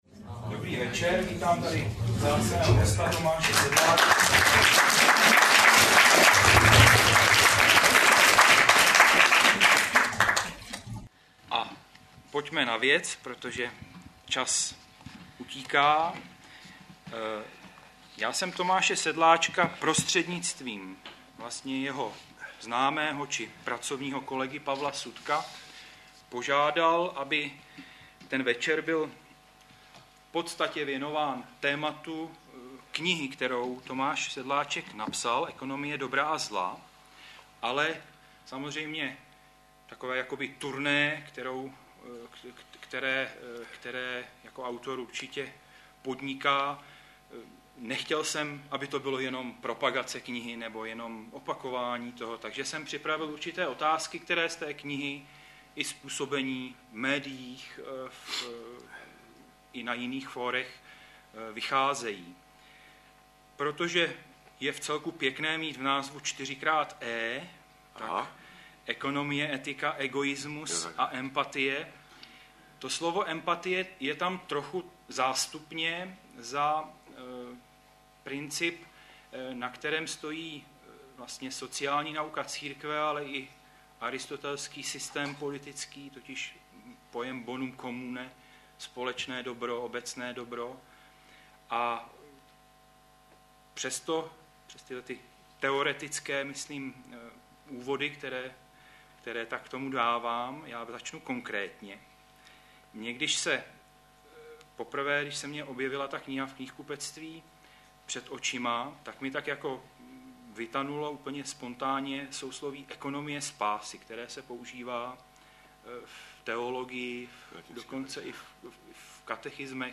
Zvukový záznam besedy
18. listopadu 2010 proběhla další beseda z cyklu Iniciativy 17–11. Hostem setkání byl ekonom Tomáš Sedláček.